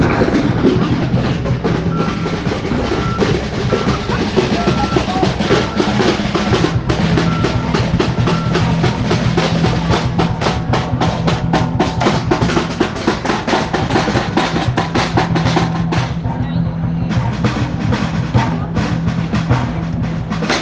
9:50 Calientan motores quienes harán sonar la batucada. Su sonido compite con el de la maquinaria que realiza las obras de la avenida Enrique Díaz de León.
batucada.ogg